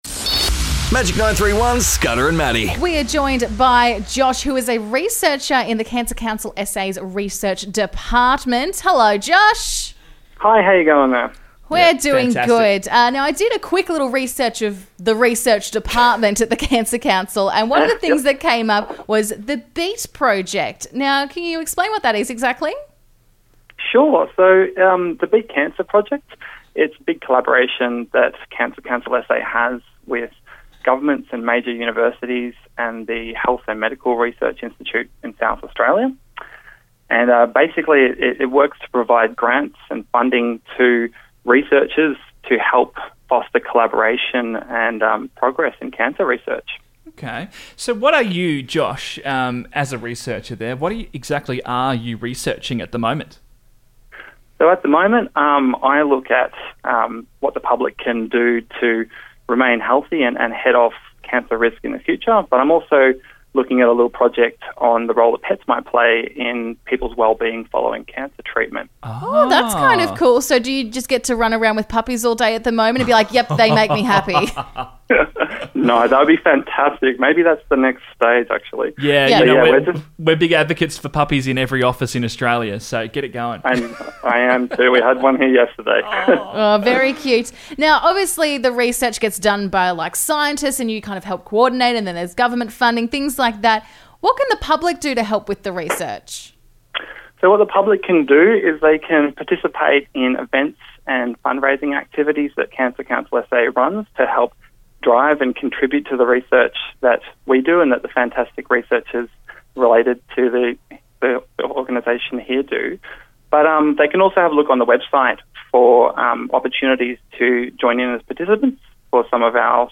As part of our 28k Relay Your Way walk on the weekend, we caught up with members of the Cancer Council SA to chat about what they do.